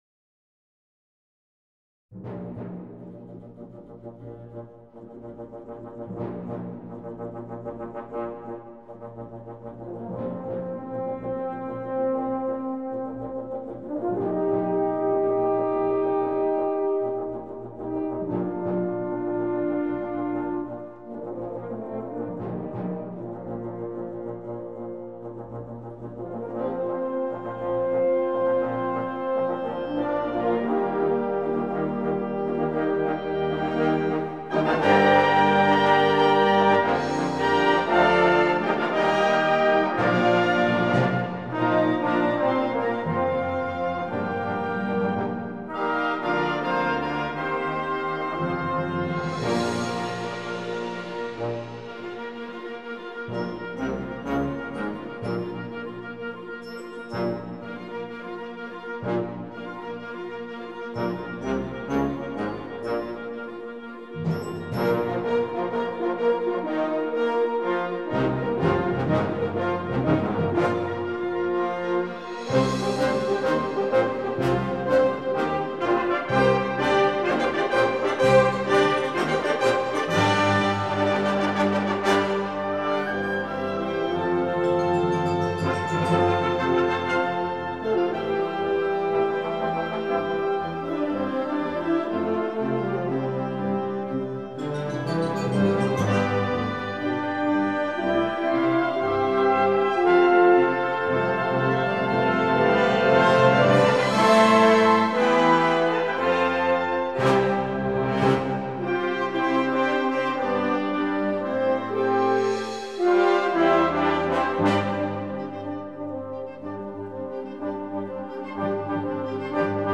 Gattung: Filmmusik
A4 Besetzung: Blasorchester PDF